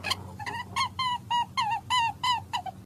Kelinci_Suara.ogg